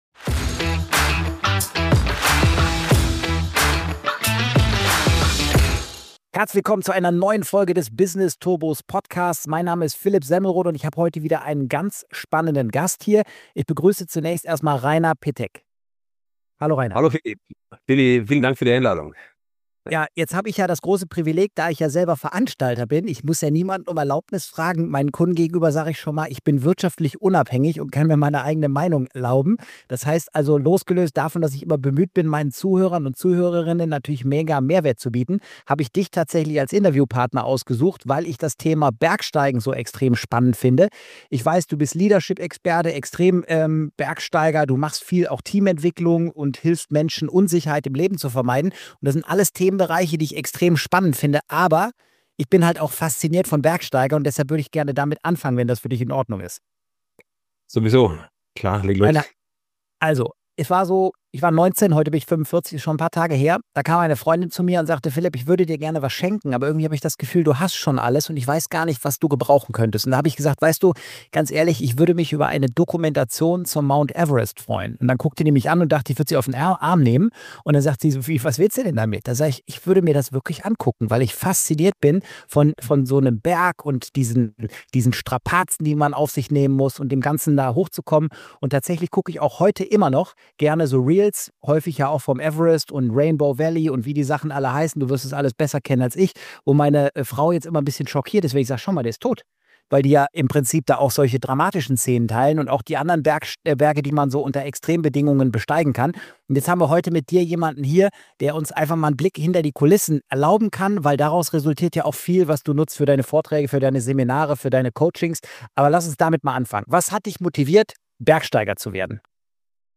#055 - INTERVIEW